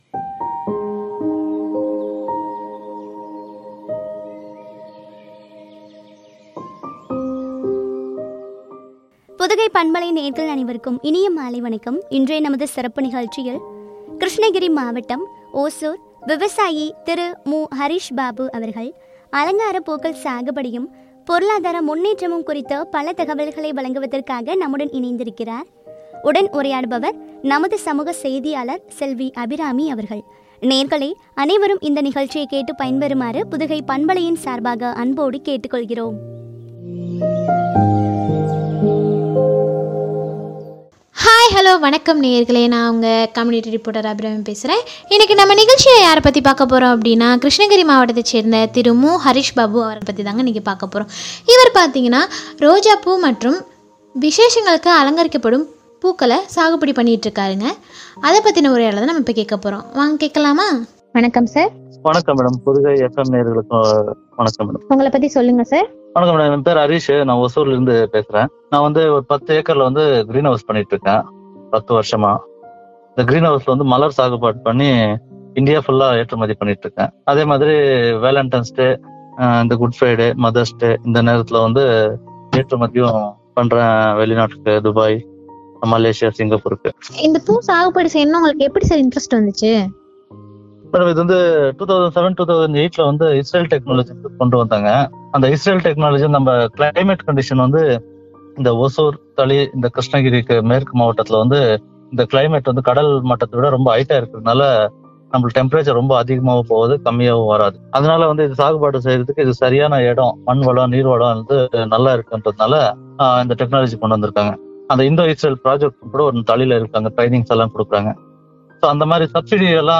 பொருளாதார முன்னேற்றமும் பற்றிய உரையாடல்.